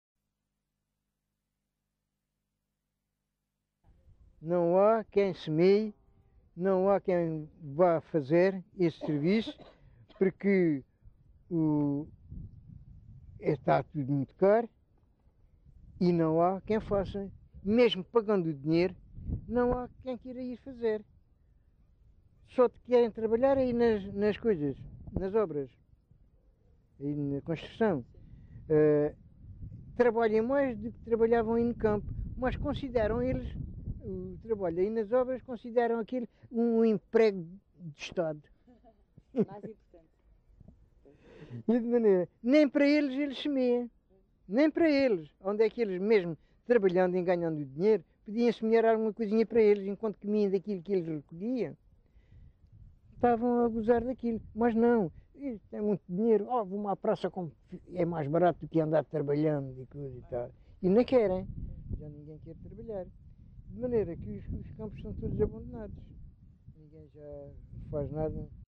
LocalidadePorches (Lagoa, Faro)